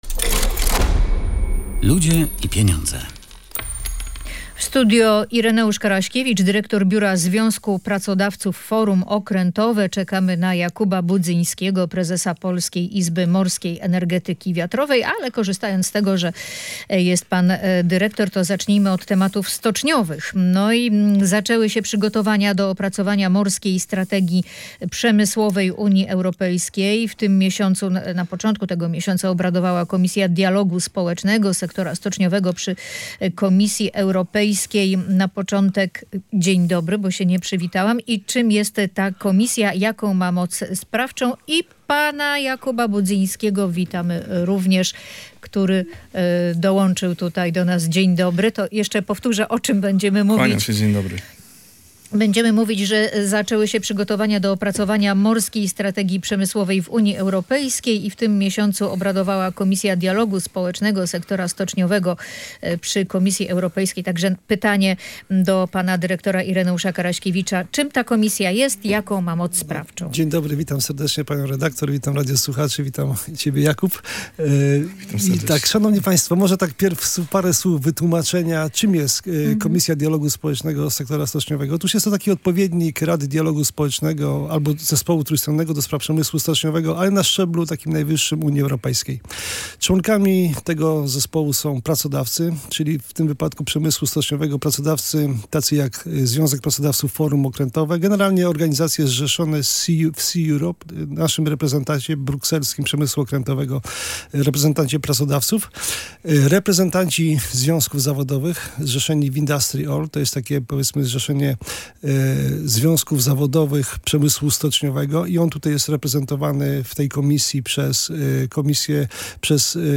– Nie oznacza to, że zamiast do Polski prąd popłynie do Danii – wyjaśniali goście audycji „Ludzie i Pieniądze”.